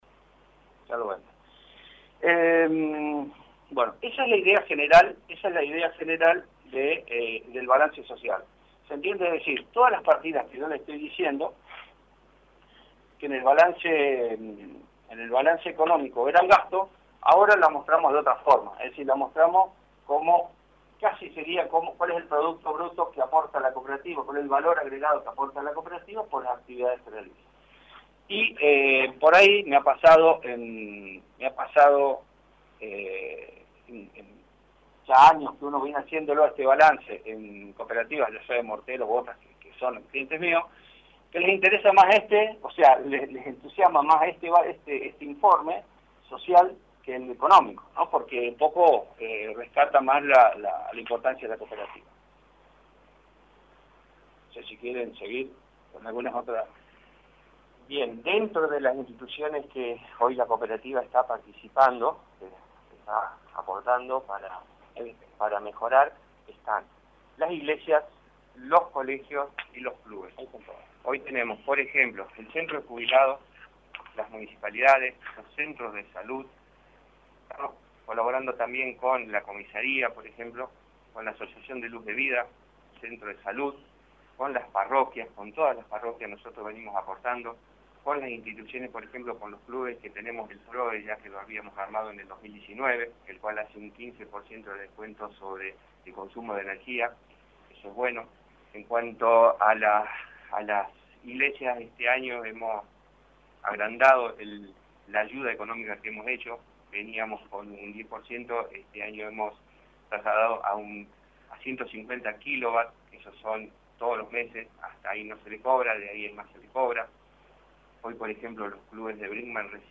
Autoridades de la Cooperativa de Brinkmann brindaron en conferencia de prensa un Informe Social de la asistencia a distintas instituciones de la localidad y pueblos vecinos.